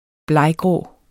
Udtale [ ˈblɑjˌgʁɔˀ ]